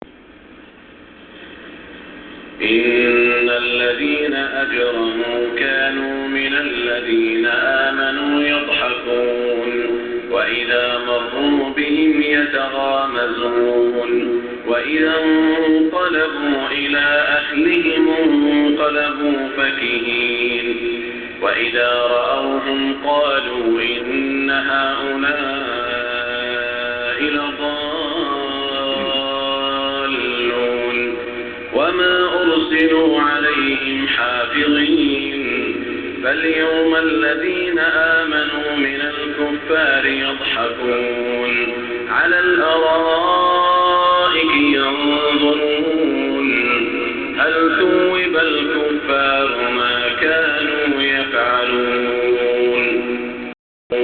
صلاة المغرب -12-1427هـ من سورة المطففين > 1427 🕋 > الفروض - تلاوات الحرمين